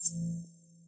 warning_pulse.ogg